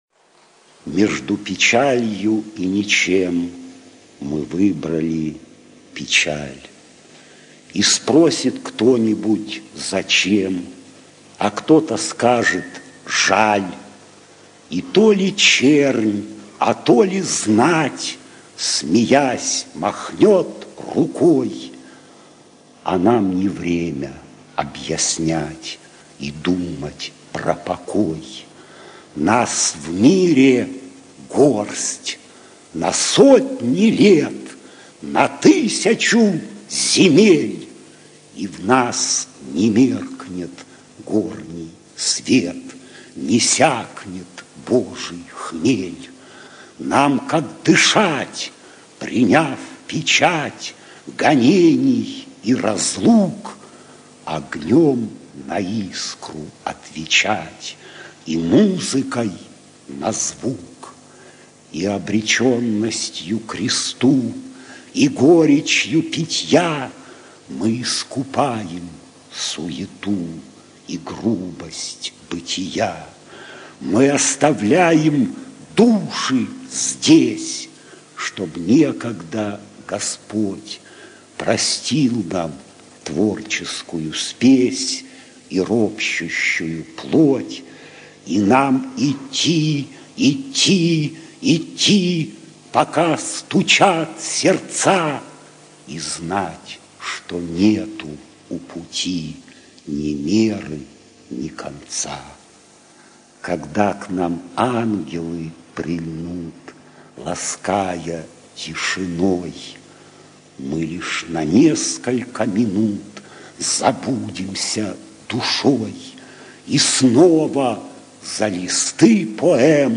boris-chichibabin-mezhdu-pechalyu-i-nichem-chitaet-avtor